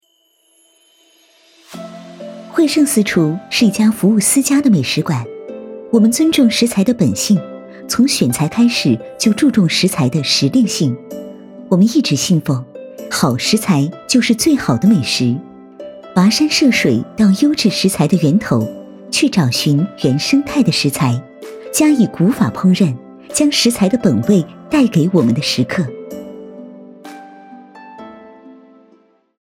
v385-【广告】私厨
女385温柔知性配音 v385
v385--广告-私厨.mp3